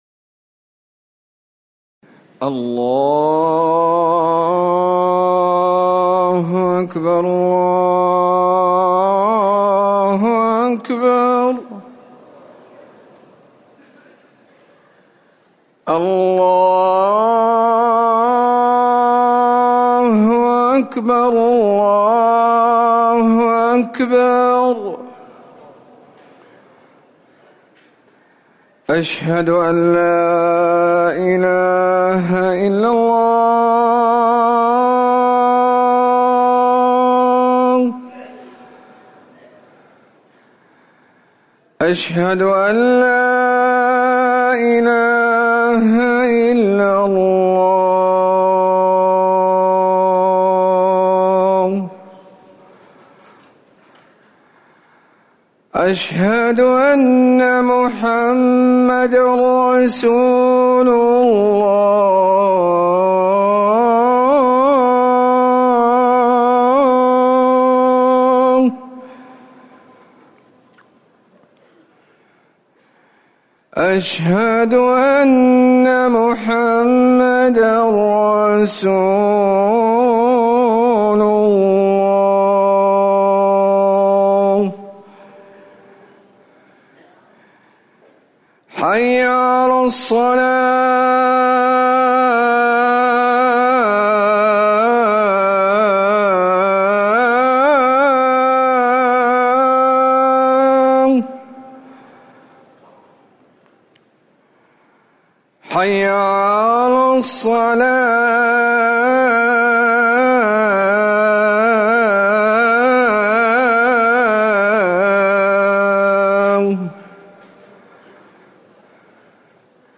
أذان المغرب - الموقع الرسمي لرئاسة الشؤون الدينية بالمسجد النبوي والمسجد الحرام
تاريخ النشر ٧ صفر ١٤٤١ هـ المكان: المسجد النبوي الشيخ